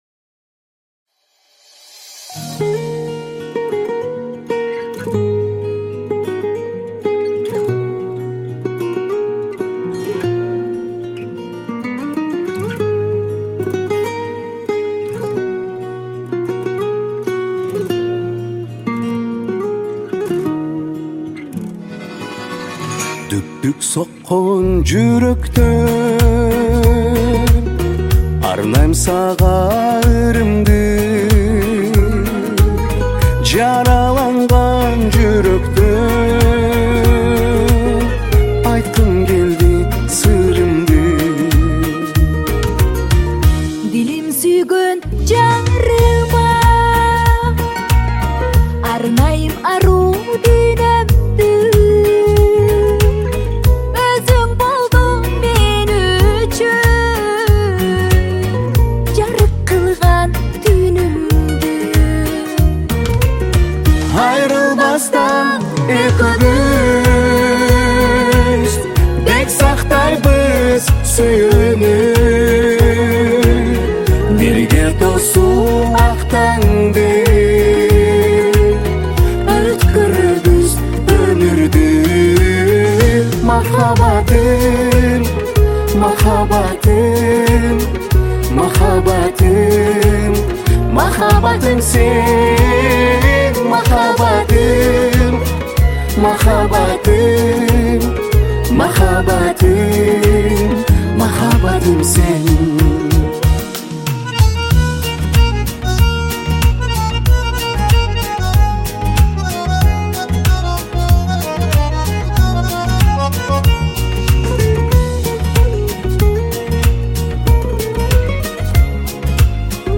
киргизская музыка
Кыргызские песни